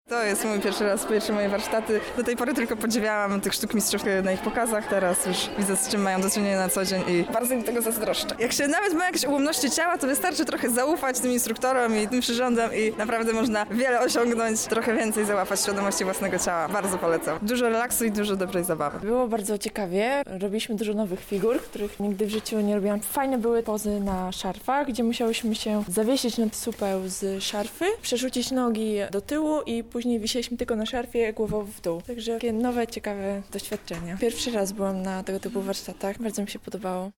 rozmawiała z uczestnikami o ich wrażeniach z warsztatów.